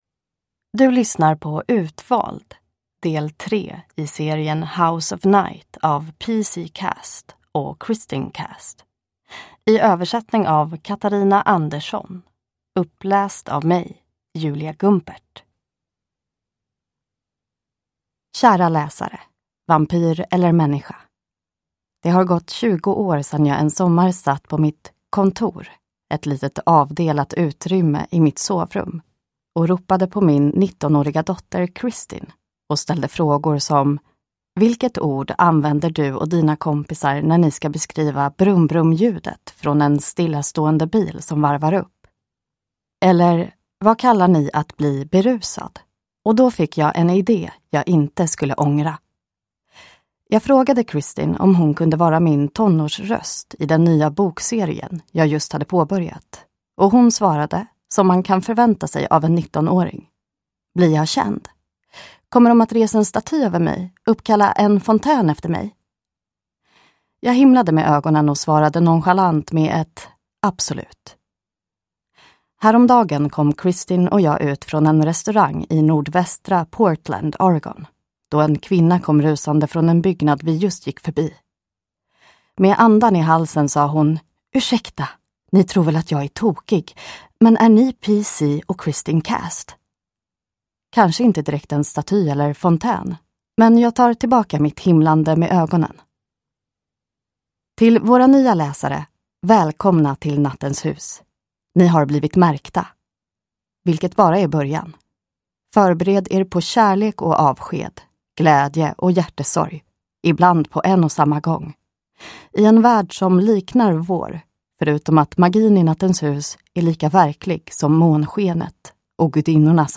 Utvald – Ljudbok